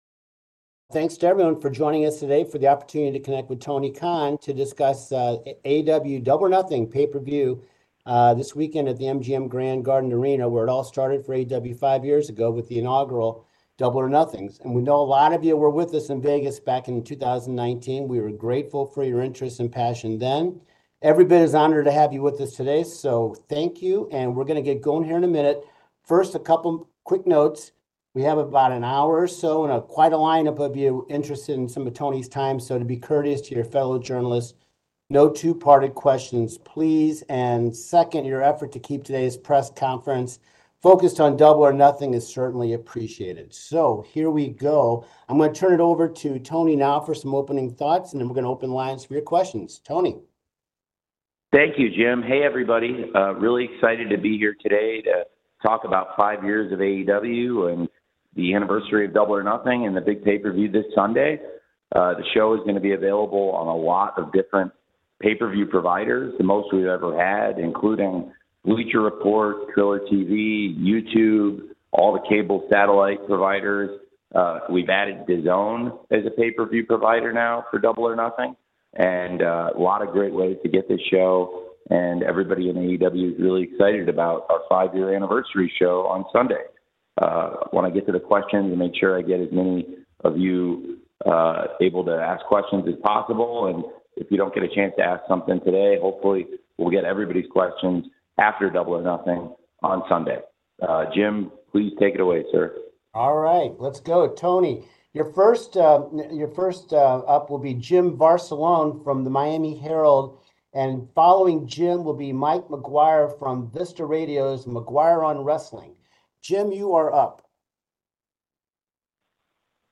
AEW Double or Nothing 2024 Media Call
Tony Khan speaks with the media ahead of AEW Double or Nothing 2024 taking place on Sunday, May 26, 2024 at the MGM Grand Garden Arena in Las Vegas, NV.